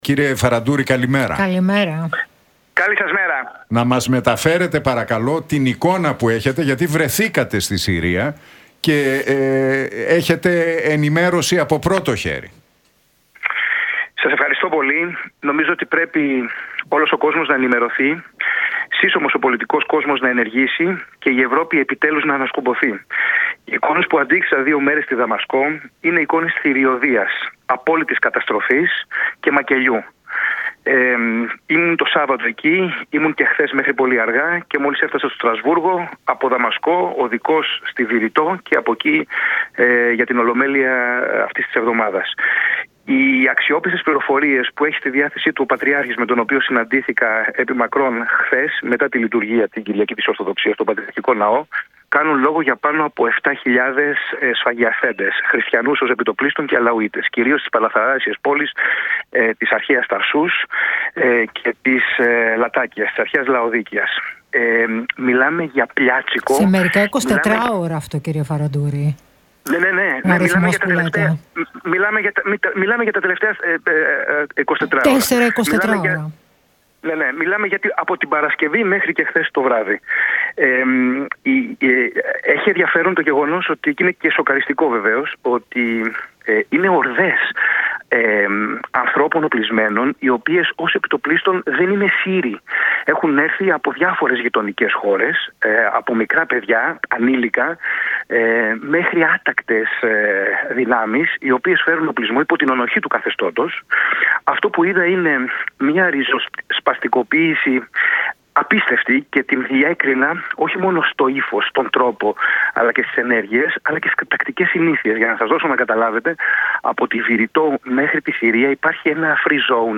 Φαραντούρης στον Realfm 97,8 για Συρία: Αντίκρισα εικόνες θηριωδίας, απόλυτης καταστροφής και μακελειού